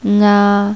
There are 19 initials in Cantonese: (All are pronounced in tone 1 HL)
As initials cannot be pronounced by itself, vocal element "a" is added to the initial to form a complete syllable.